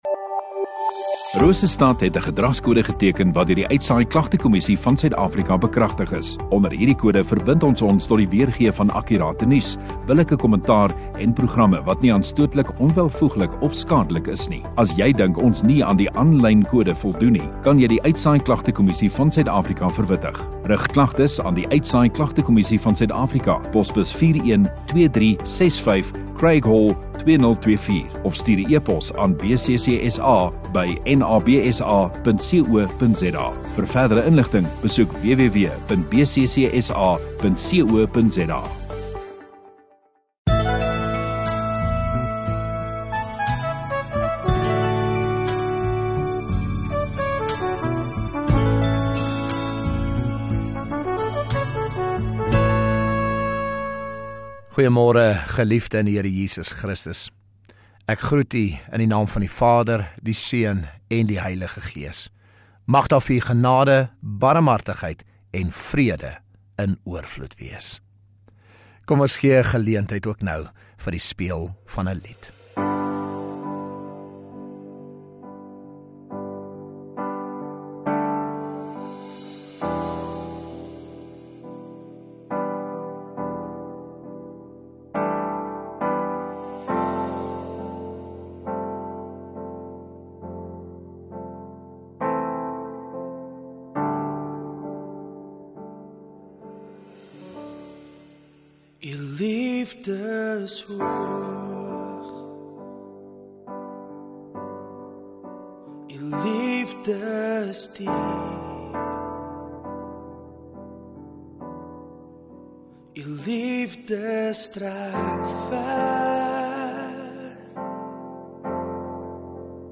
9 Jun Sondagoggend Erediens